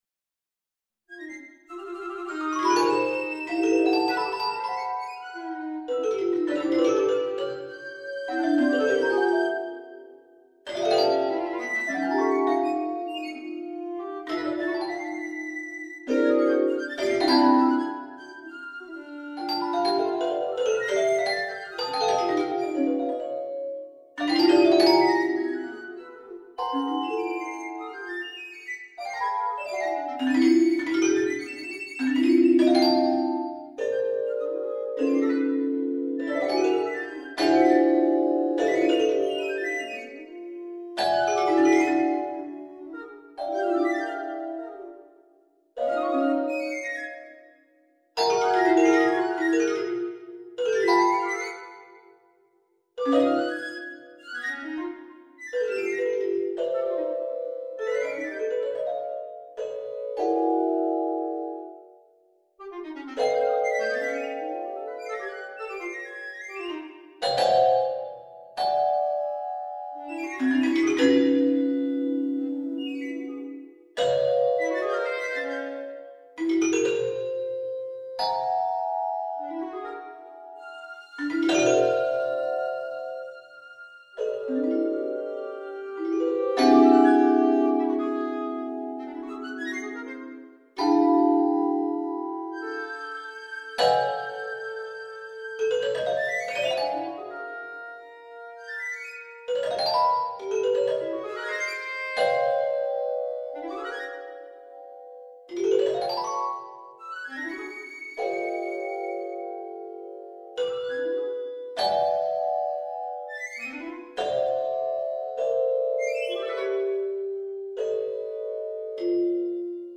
Trio für Piccoloflöte, Bassklarinette und Celesta (2021)
(Noteperformer-Version)
Nach einer Werkschau über die letzten hundert Jahre hinweg, empfand ich die Kombination mit einem weiteren, sehr hohen Blasinstrument und, als Kontrast, einem eher perkussiven, dabei gleichzeitig melodiösem Instrument sehr reizvoll; was letztlich zur vorliegenden Besetzung geführt hat.